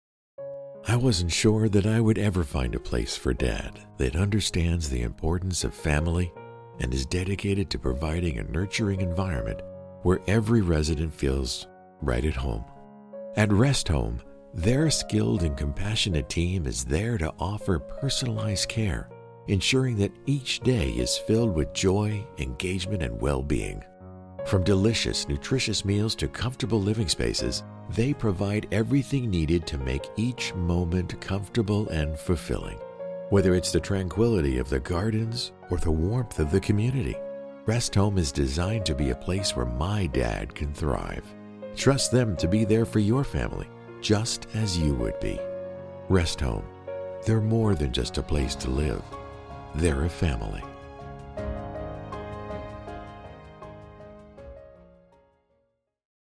Professional Voice Artist
English - USA and Canada
Middle Aged
Senior